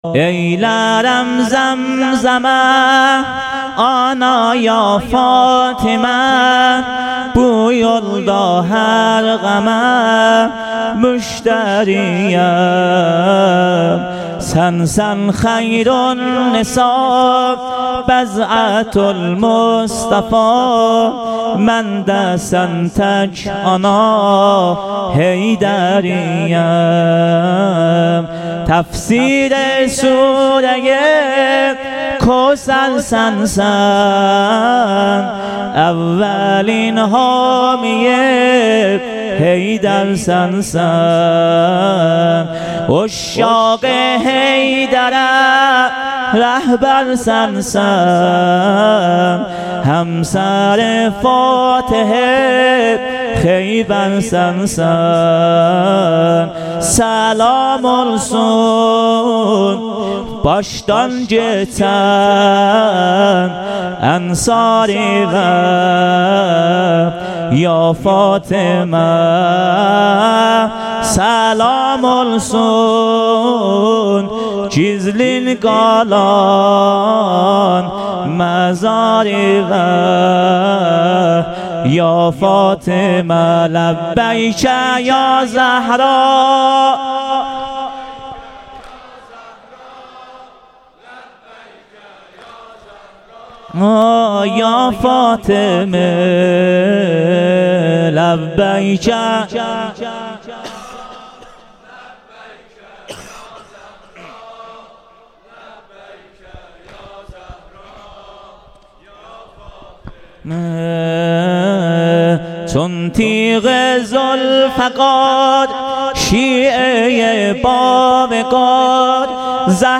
شب دوم- بخش دوم سینه زنی